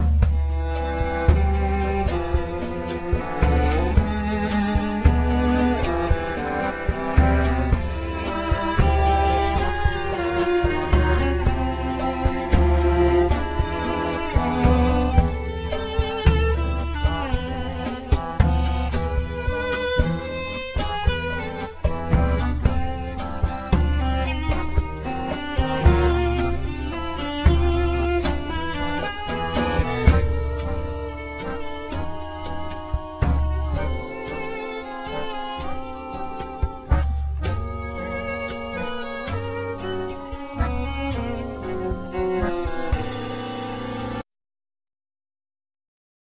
Accordeon,Vocals
Violin,Viola,Vocals
Cello
Drums,Percussions